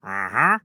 Minecraft Version Minecraft Version latest Latest Release | Latest Snapshot latest / assets / minecraft / sounds / mob / wandering_trader / idle4.ogg Compare With Compare With Latest Release | Latest Snapshot